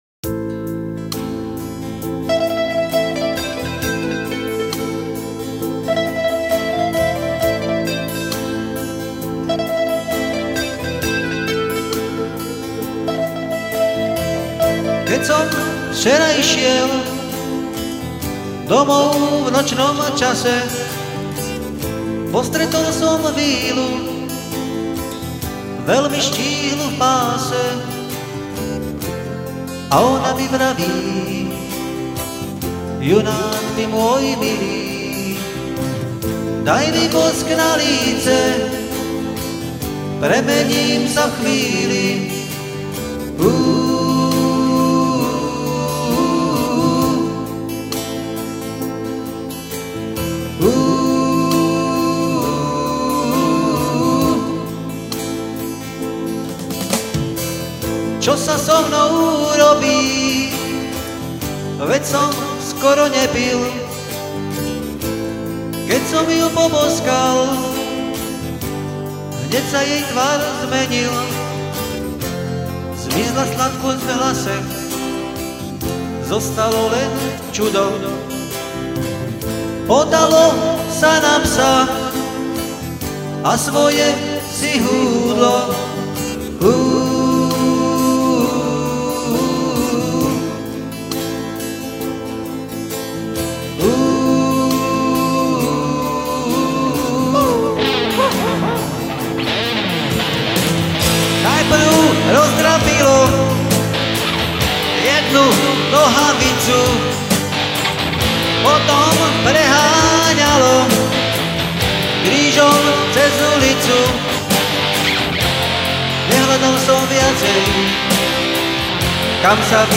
Záhorácke ľudové piesne - Piesne POP - Balada pod psa
sólo gitaru